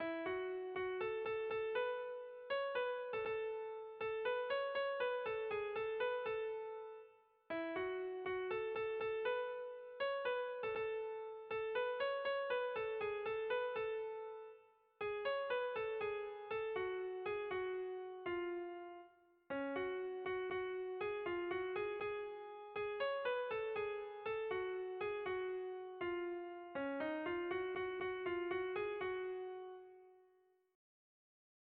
Erlijiozkoa
Zortziko handia (hg) / Lau puntuko handia (ip)
A-A-B-B2